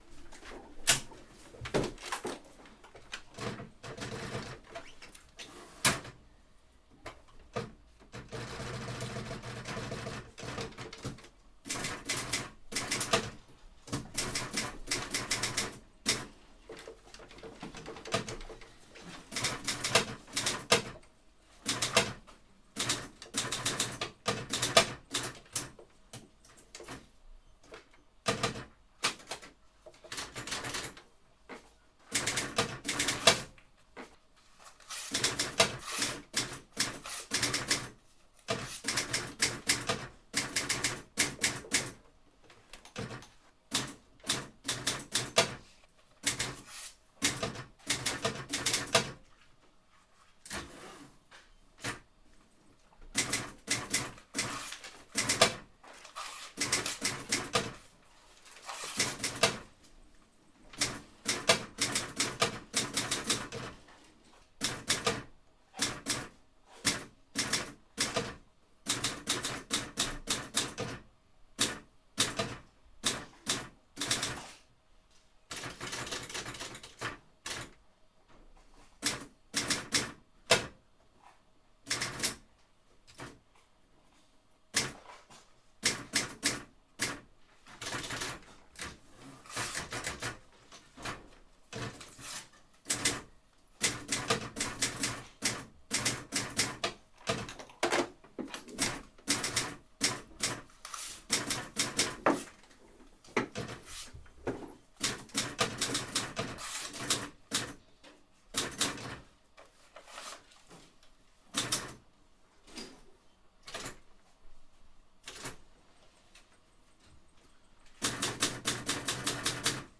typewriter-sound